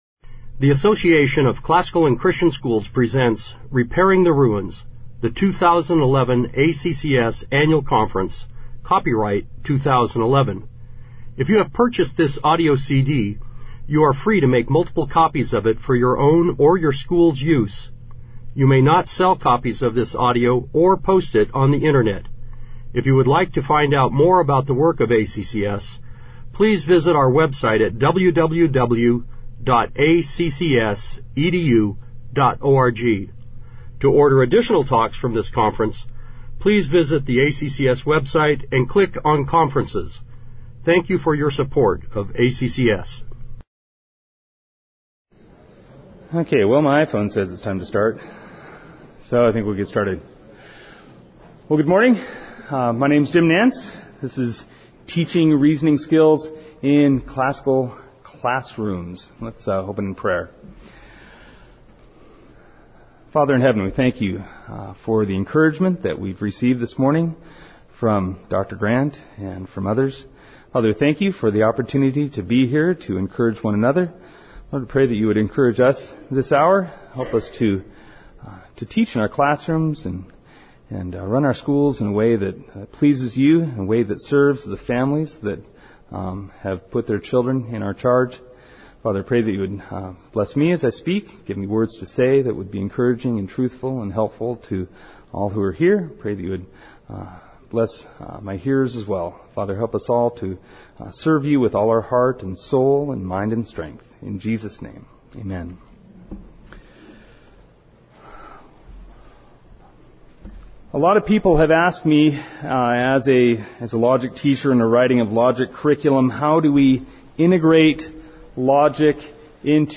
2011 Workshop Talk | 0:55:17 | All Grade Levels, General Classroom, Logic